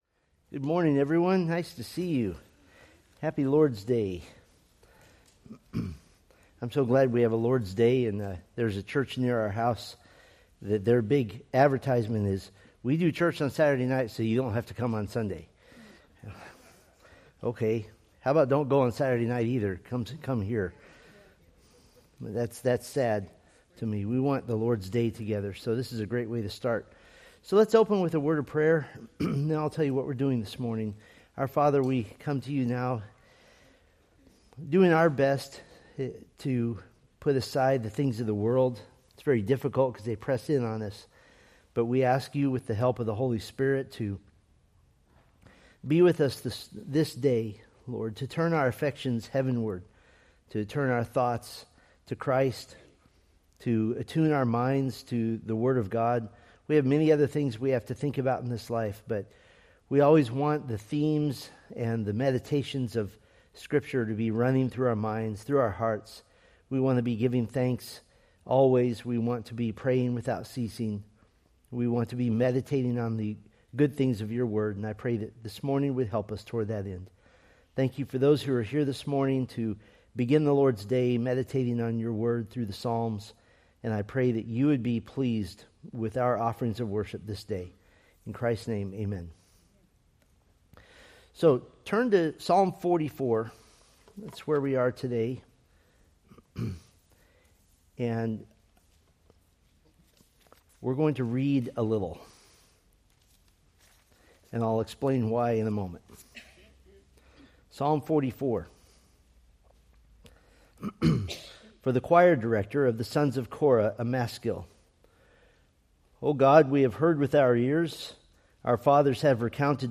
Date: Nov 23, 2025 Series: Psalms Grouping: Sunday School (Adult) More: Download MP3